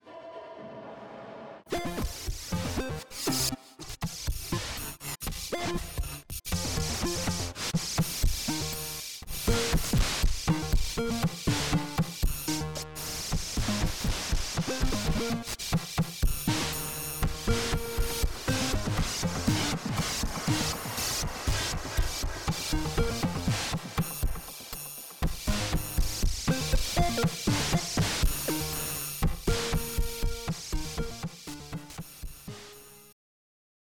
The audio below is Tekno, BA-1, and Transit 2. Featuring new presets that everyone will be getting soon.